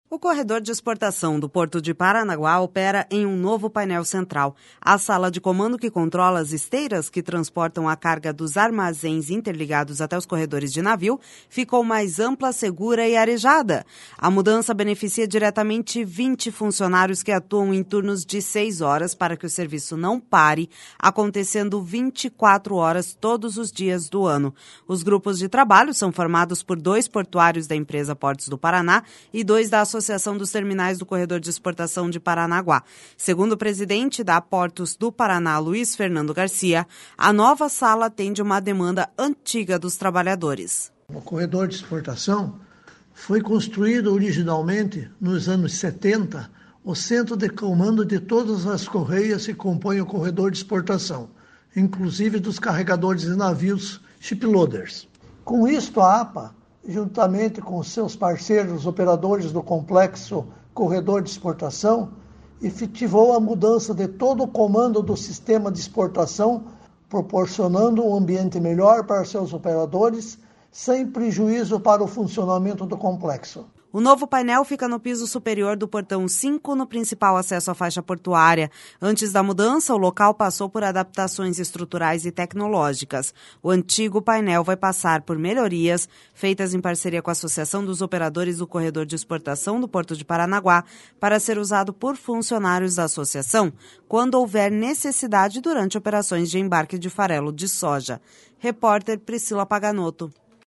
Segundo o presidente da Portos do Paraná, Luiz Fernando Garcia, a nova sala atende uma demanda antiga dos trabalhadores.// SONORA LUIZ FERNANDO GARCIA//O novo painel fica no piso superior do portão 5, no principal acesso à faixa portuária.